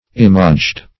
([i^]m"[asl]jd; 48); p. pr. & vb. n. Imaging.]